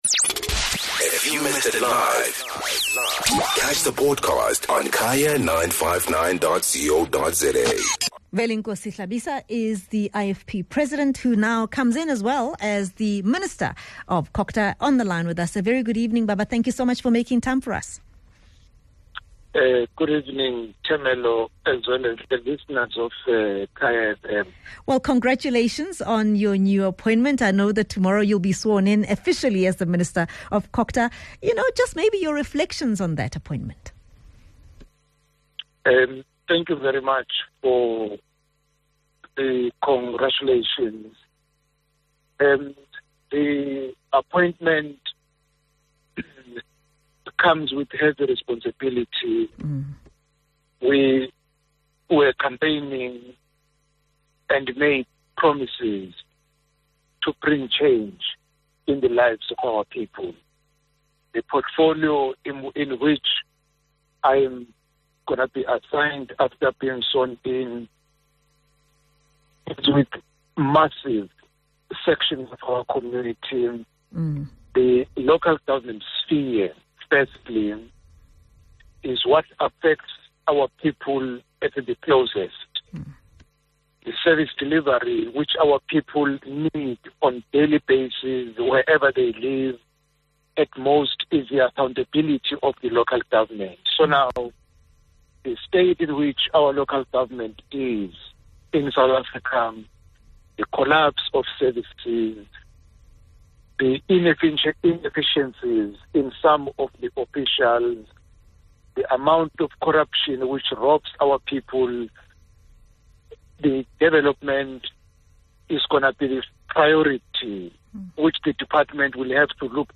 Guest: Hon Velenkosi Hlabisa - IFP Leader/ COGTA Minister